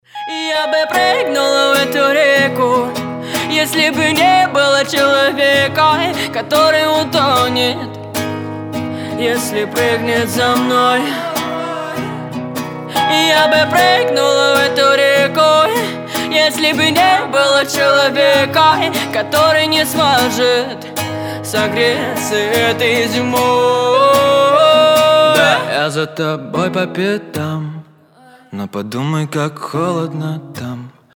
• Качество: 320, Stereo
гитара
грустные
дуэт
indie pop
депрессивные